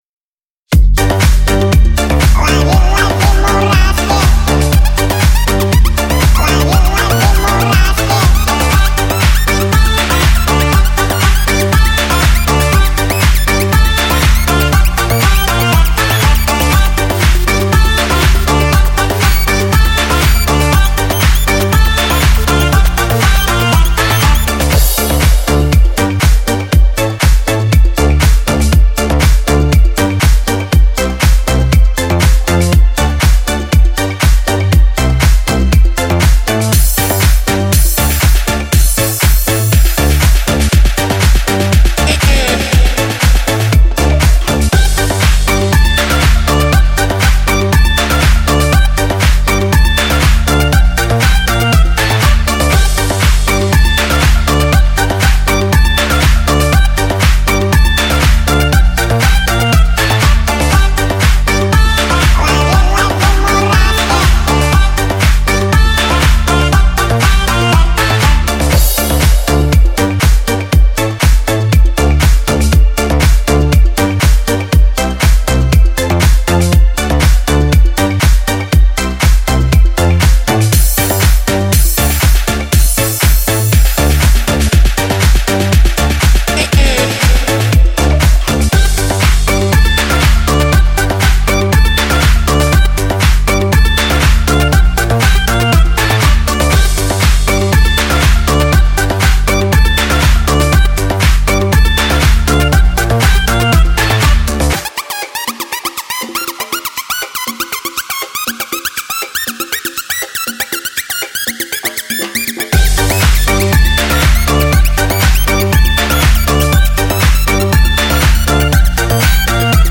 Текст песни